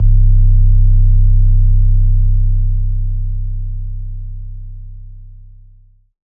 808 LEX LUGER.wav